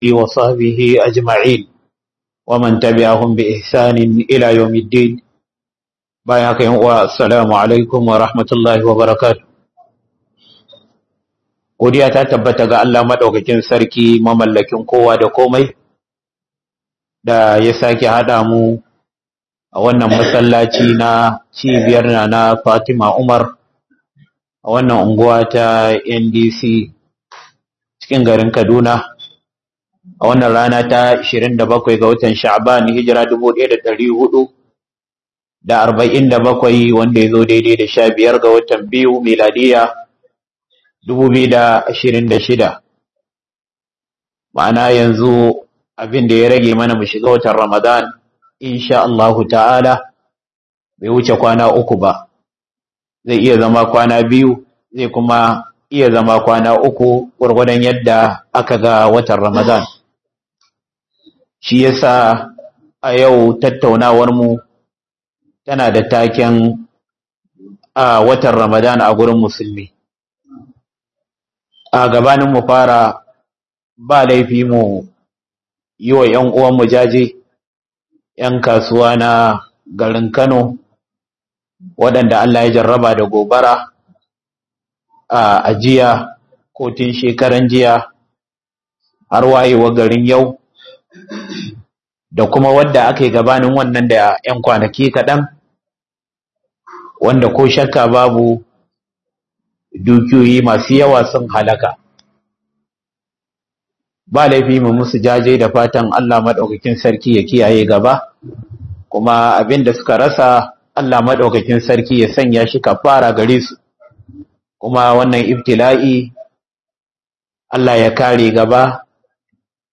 Book Muhadara